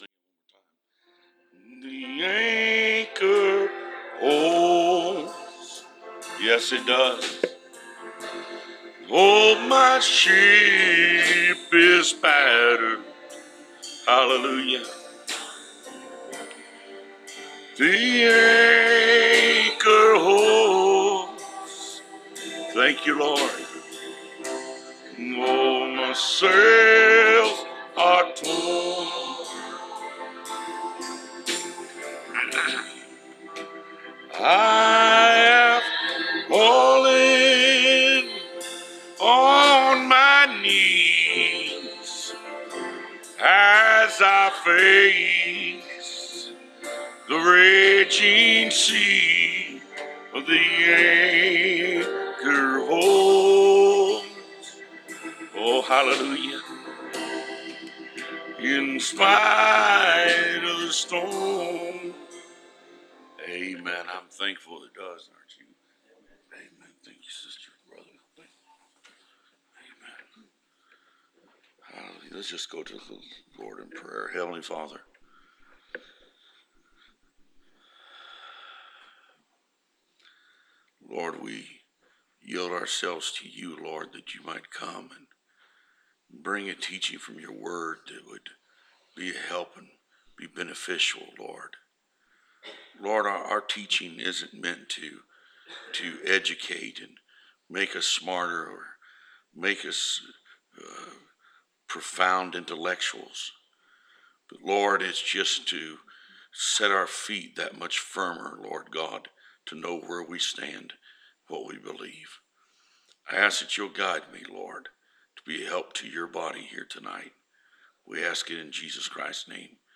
Preached August 27, 2015 Scripture List: Joel 1:4 Joel 2:25 Ezekiel 37:1-10